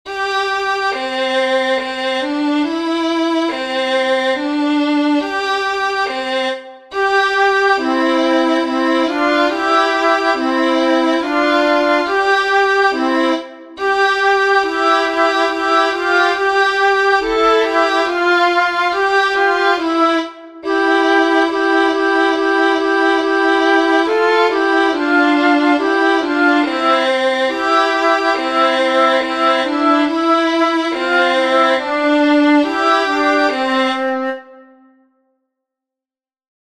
Canon_IMITATION.mp3